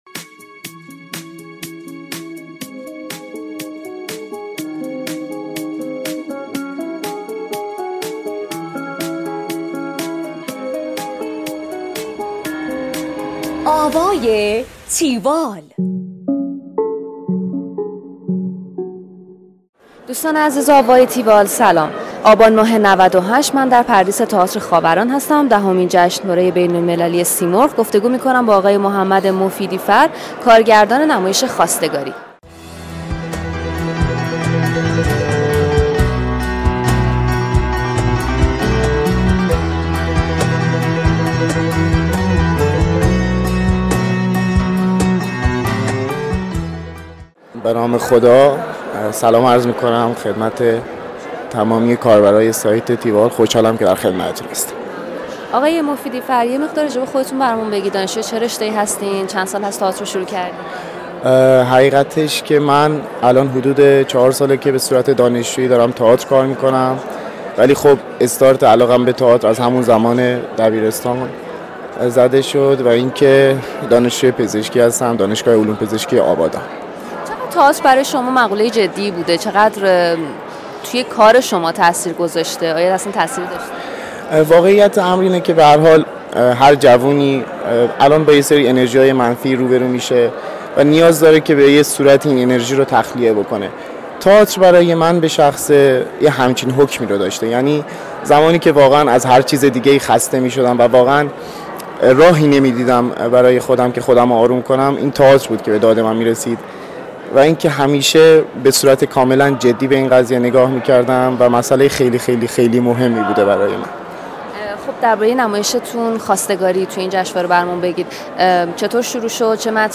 درباره نمایش خواستگاری کاری از